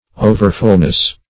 Overfullness \O"ver*full"ness\, n.